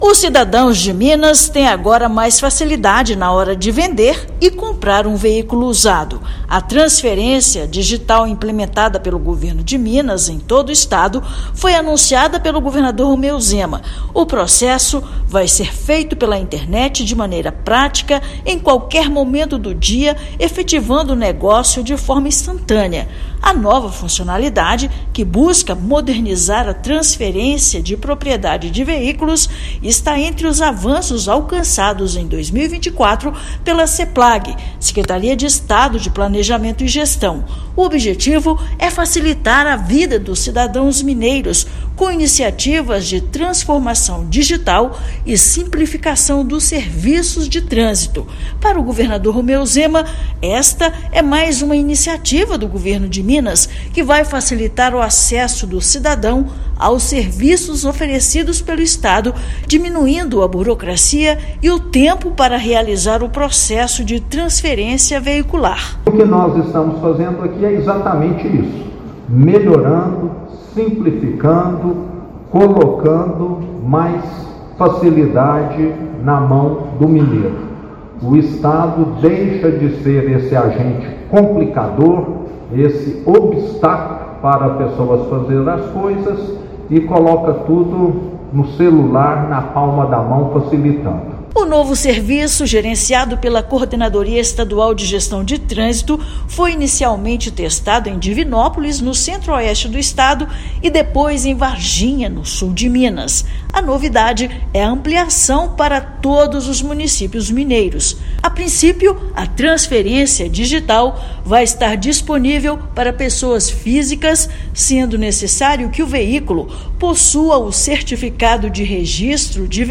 Com o novo serviço disponibilizado pela Seplag-MG, mais um avanço na transformação digital em Minas Gerais em 2024, cidadão não precisará reconhecer firma em cartório ou ir até o órgão público para concluir a transferência. Ouça matéria de rádio.